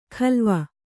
♪ khalva